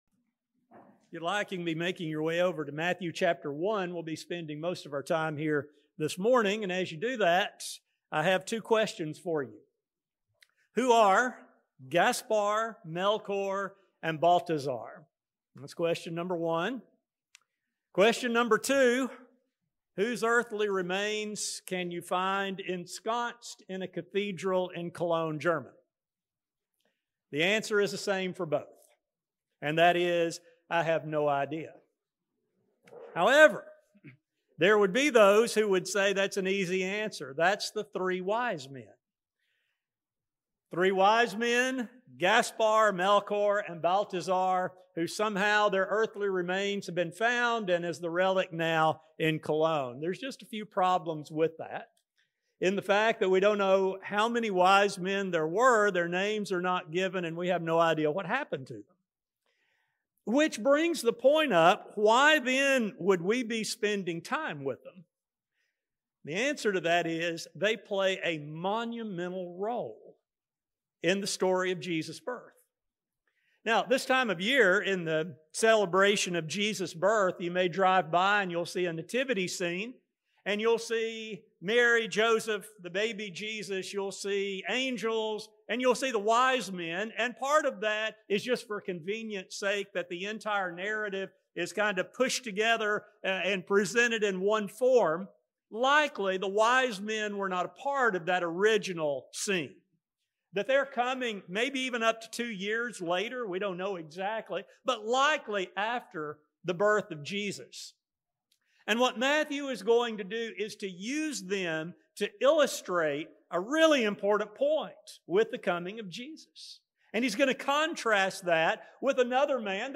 Through them, we find both fulfillment of ancient prophecy as well as a striking contrast with a foolish leader who rejected God’s gift to humanity. This study will focus on Matthew’s unique perspective of events following the birth of Jesus and demonstrate the choice that must be made concerning one’s view of Jesus. A sermon